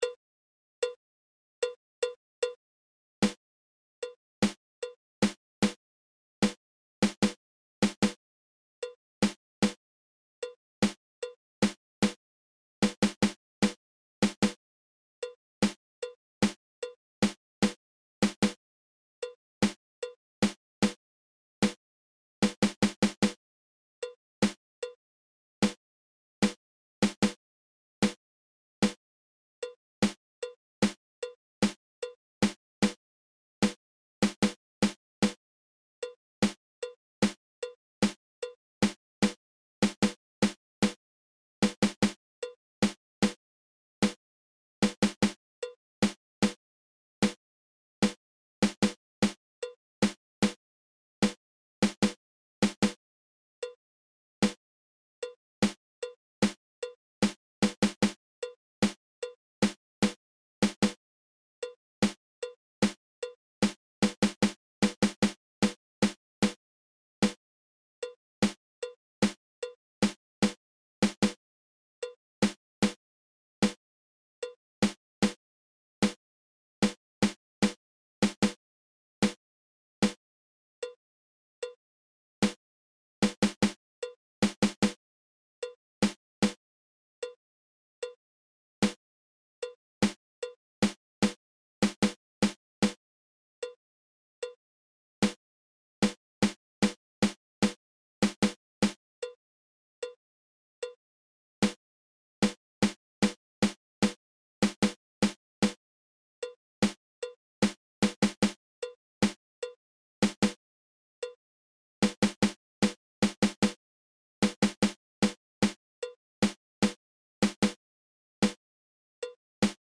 PRACTICE THIS EXERCISE WITH OUR PLAY-ALONG TRACKS!